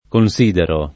considero [ kon S& dero ]